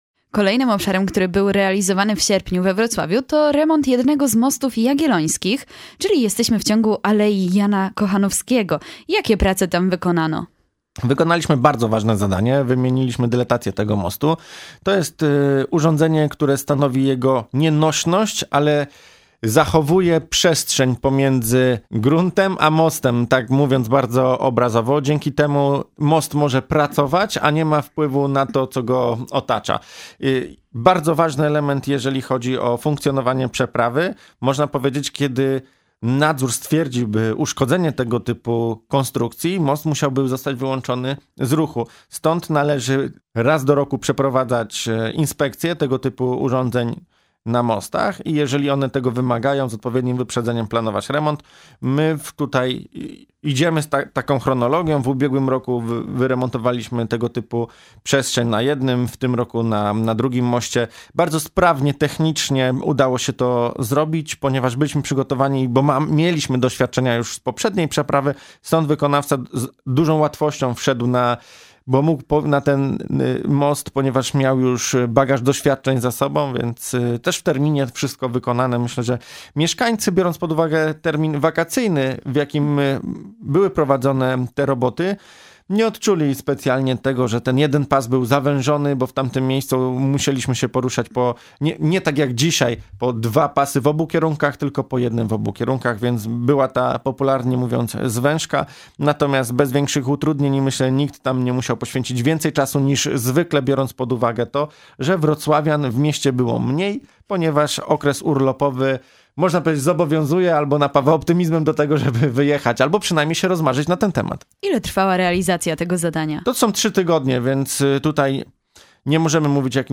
Audycja realizowana we współpracy ze spółką Wrocławskie Inwestycje.